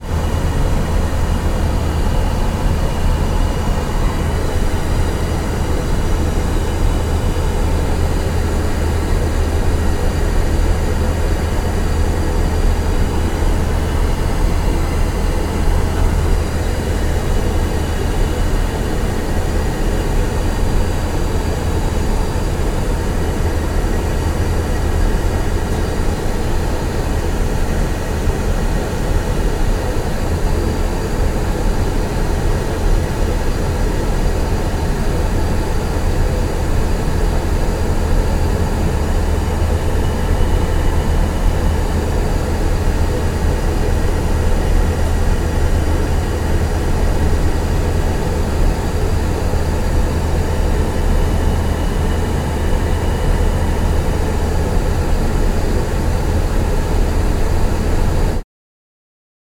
Matt Script - Airplanes & Airports - Walla & Ambience
aircraft_airplane_commercial_bathroom_room noise.ogg